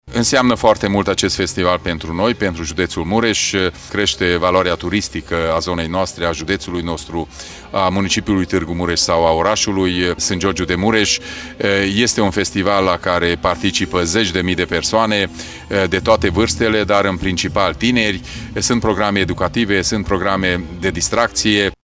Președintele Consiliului Județean Mureș, Peter Ferenc, a felicitat organizatorii și a declarat că, de 7 ani, aceștia aduc în județul Mureș mii de turiști: